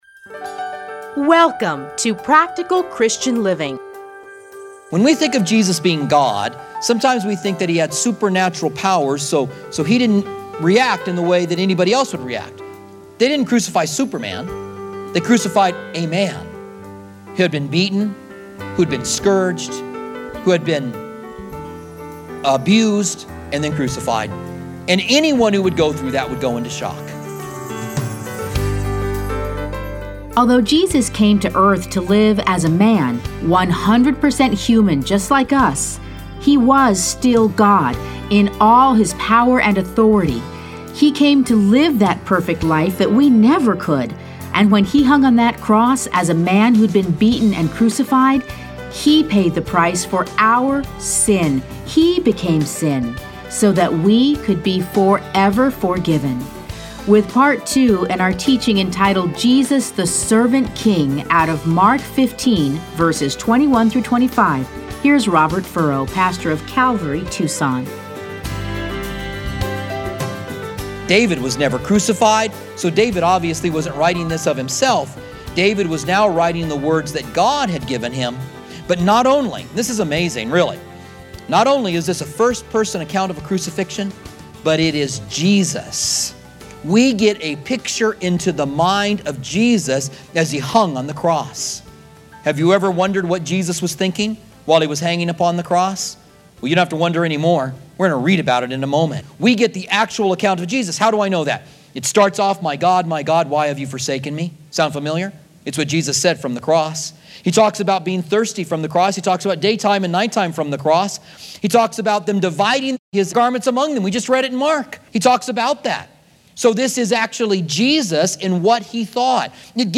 Listen to a teaching from Mark 15:21-25.